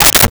Dresser Drawer Handle 02
Dresser Drawer Handle 02.wav